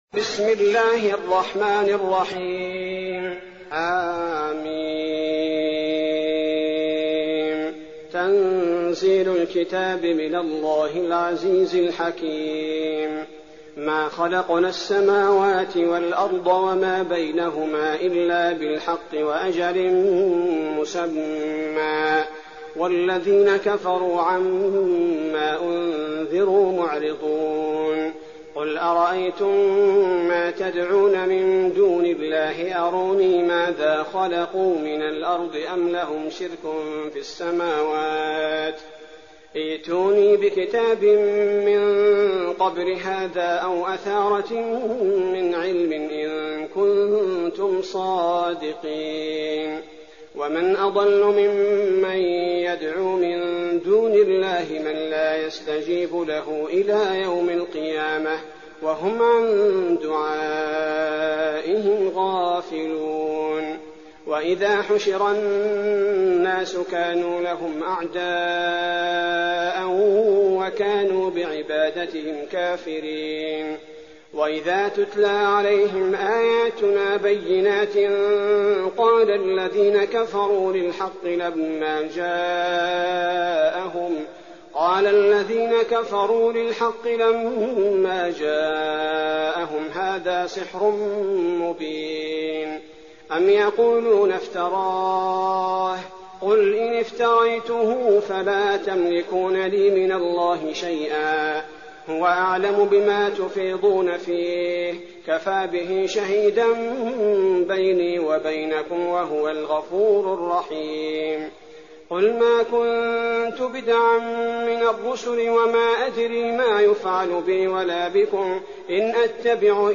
تراويح ليلة 25 رمضان 1419هـ من سور الأحقاف و محمد و الفتح (1-17) Taraweeh 25th night Ramadan 1419H from Surah Al-Ahqaf and Muhammad and Al-Fath > تراويح الحرم النبوي عام 1419 🕌 > التراويح - تلاوات الحرمين